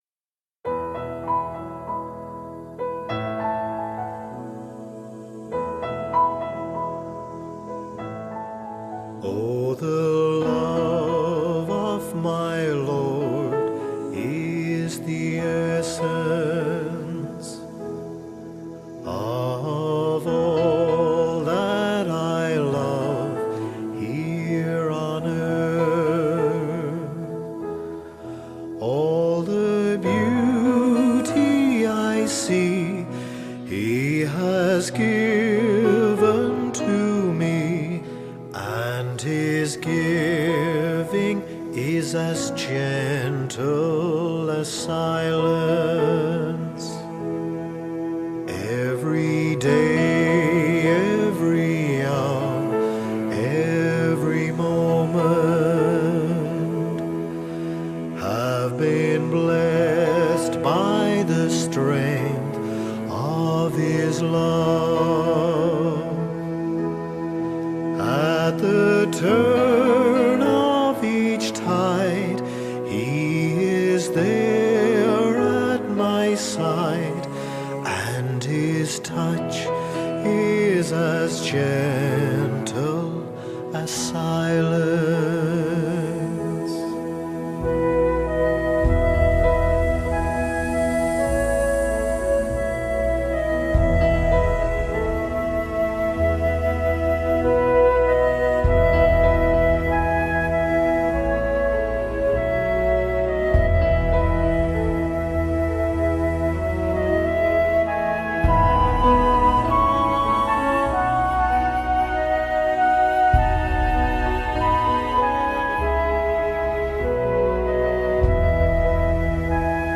in Hymns by